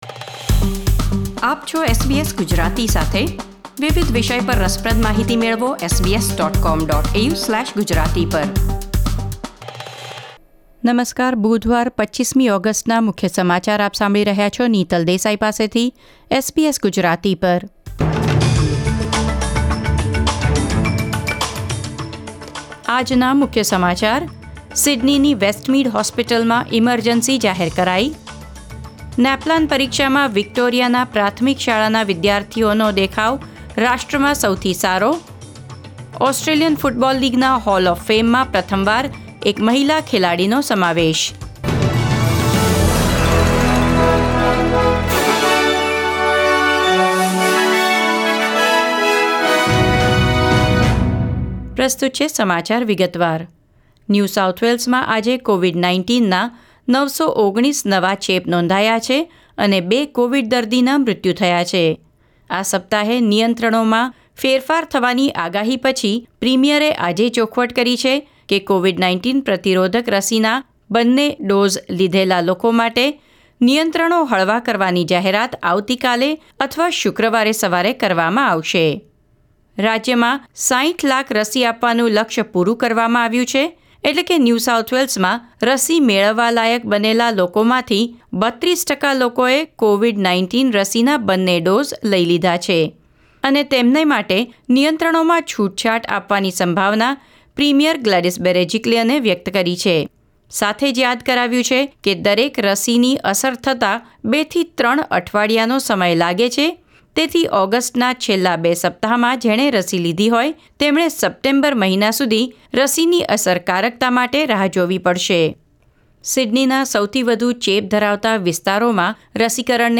SBS Gujarati News Bulletin 25 August 2021